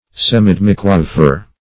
Search Result for " semidemiquaver" : The Collaborative International Dictionary of English v.0.48: Semidemiquaver \Sem`i*dem"i*qua`ver\, n. (Mus.) A demisemiquaver; a thirty-second note.